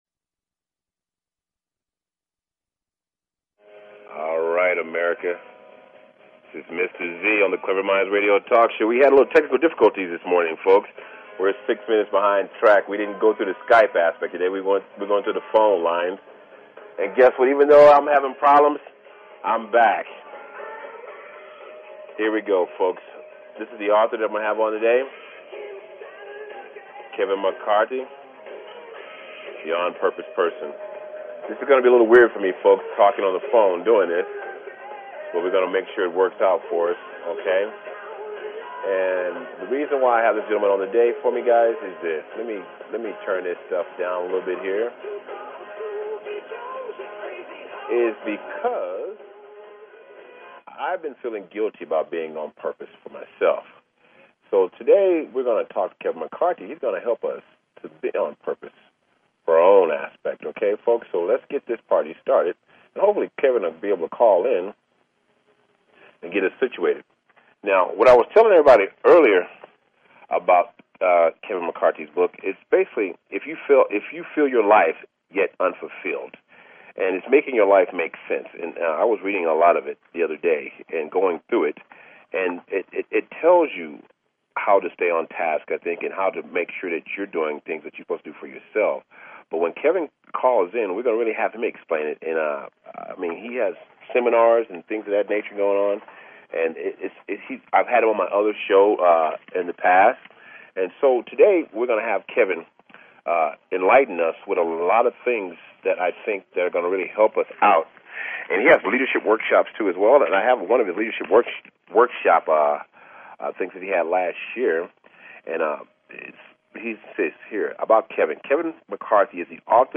Talk Show Episode, Audio Podcast, Cleverminds and Courtesy of BBS Radio on , show guests , about , categorized as